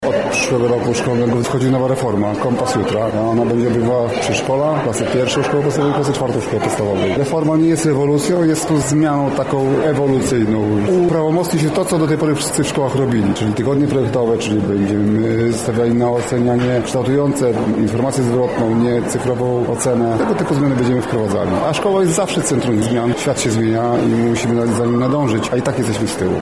Tomasz Szabłowski-mówi Tomasz Szabłowski, Lubelski Kurator Oświaty